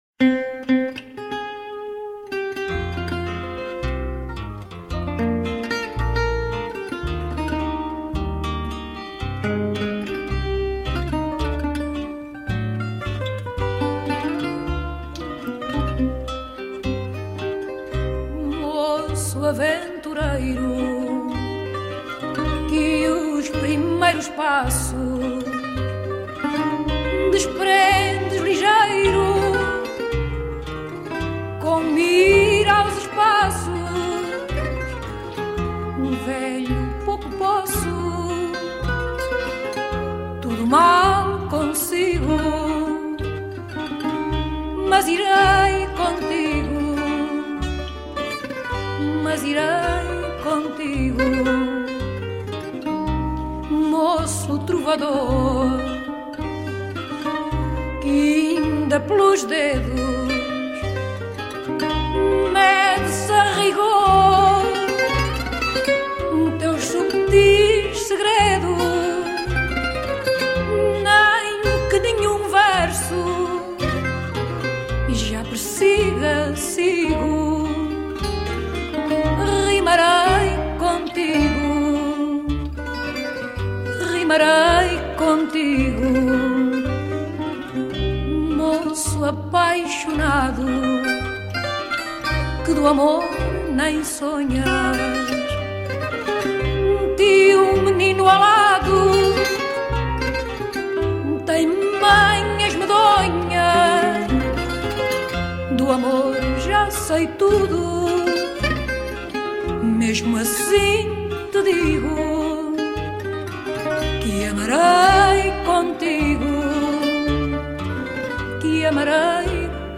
Belle guitare portugaise.
from → Adorable, Fado